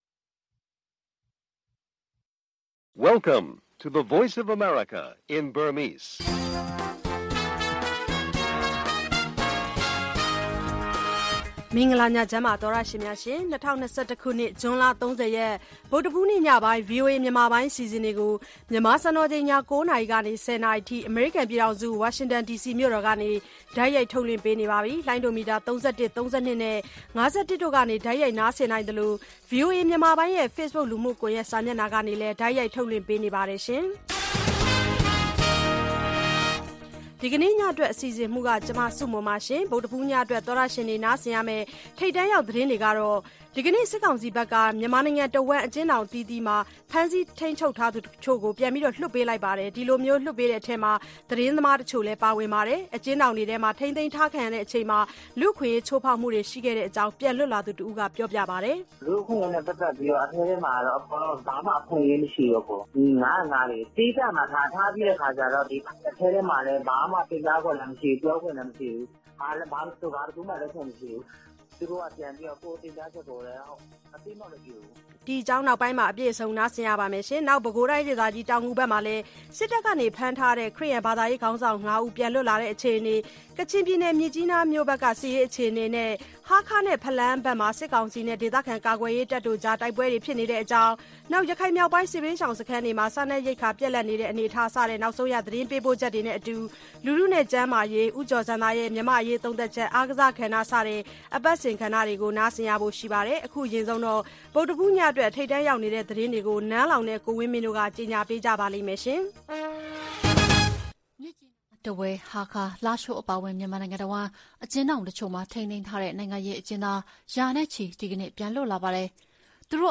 VOA ရေဒီယို ညပိုင်း ၉း၀၀-၁၀း၀၀ တိုက်ရိုက်ထုတ်လွှင့်မှု